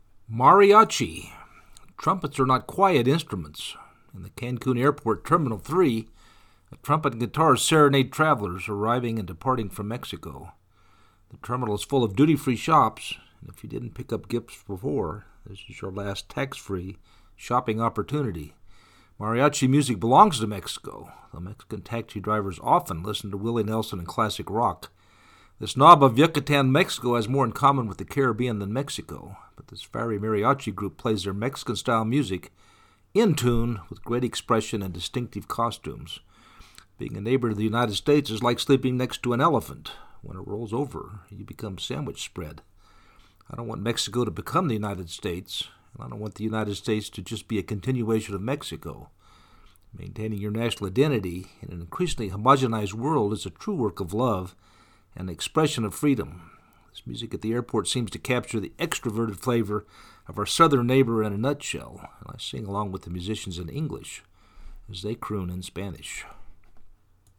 Mariachi Cancun Airport
Trumpets are not quiet instruments. In the Cancun Airport, Terminal Three, a trumpet and guitars serenade travelers arriving and departing from Mexico.
This knob of Yucatan, Mexico has more in common with the Caribbean than Mexico but this fiery Mariachi group plays their Mexican style music, in tune, with great expression and distinctive costumes.
This music at the airport seems to capture the extroverted flavor of our southern neighbor in a nutshell and I sing along with the musicians in English, as they croon in Spanish.
mariachi-5.mp3